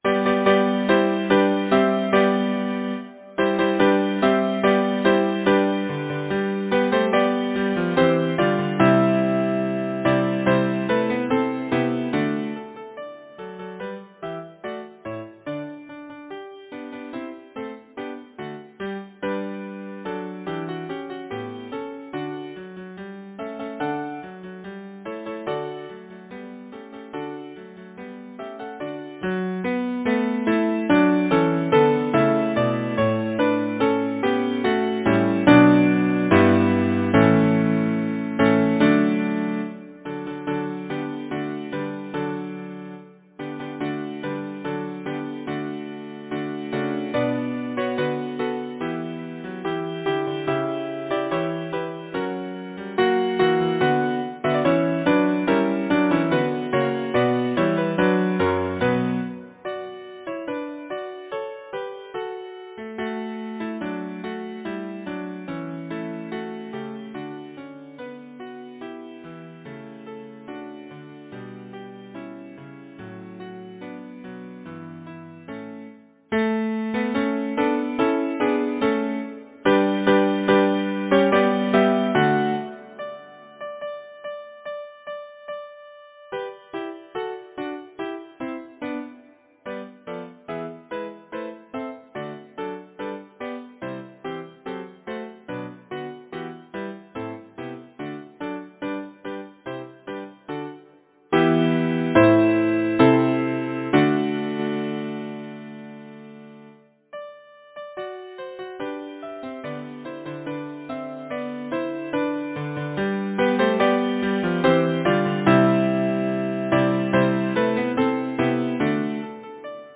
Title: The Urchins’ Dance Composer: John Liptrot Hatton Lyricist: Number of voices: 4vv Voicing: SATB Genre: Secular, Partsong
Language: English Instruments: A cappella